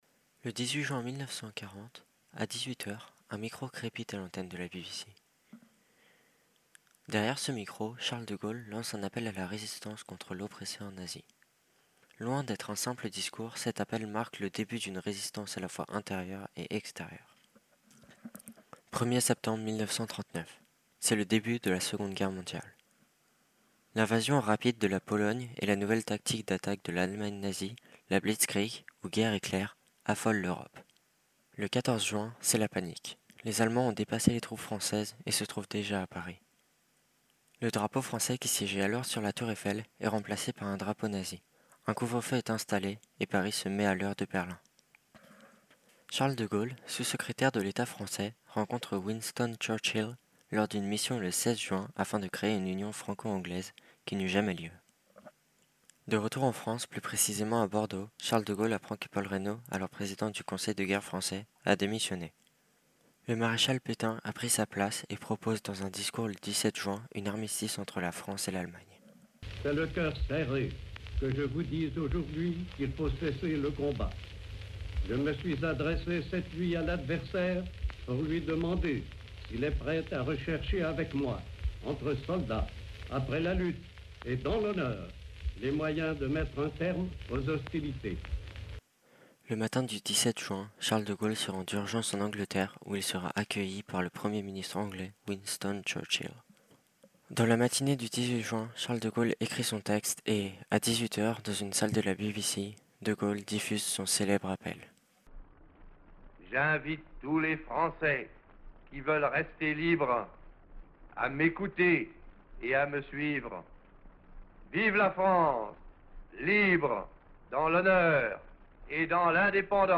Le résumé audio